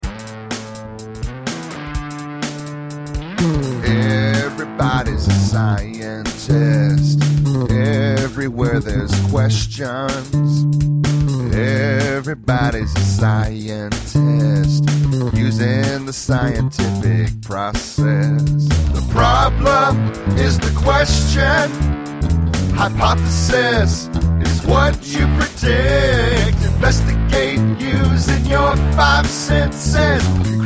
Science Song Lyrics and Sound Clip
Vocal